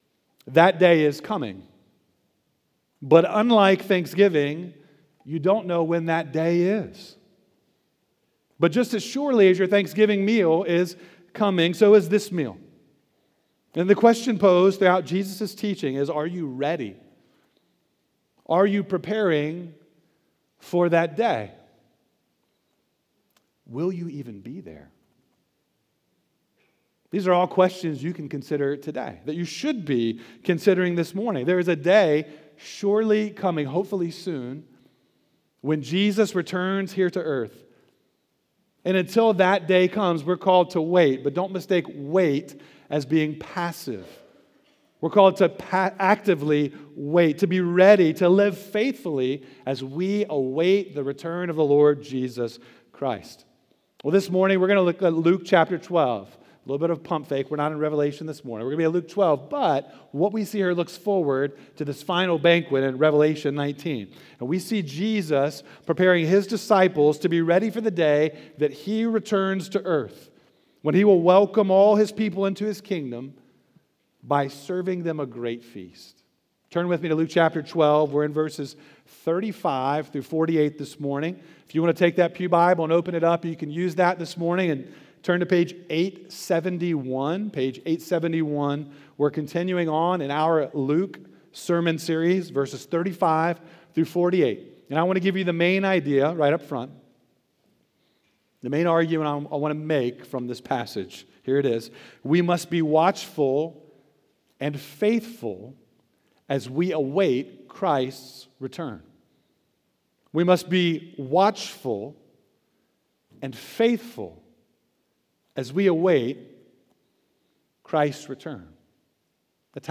Sermons by Oakhurst Baptist Church Sermons